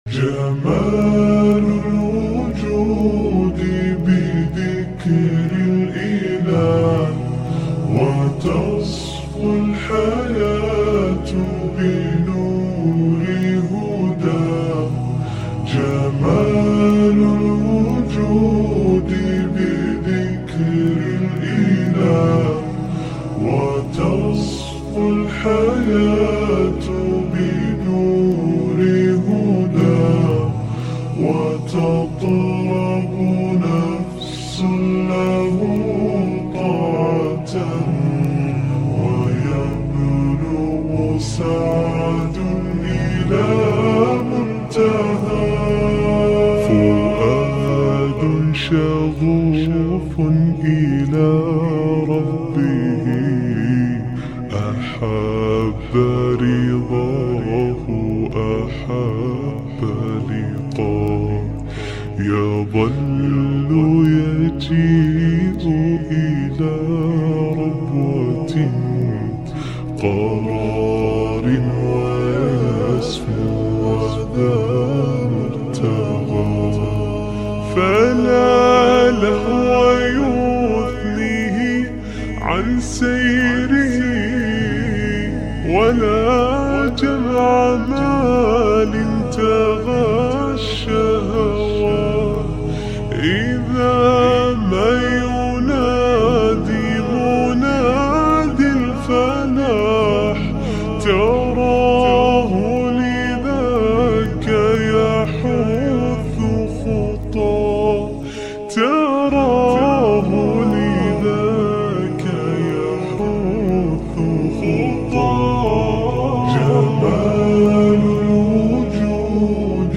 Arabic Nasheed (Slowed+Reverb)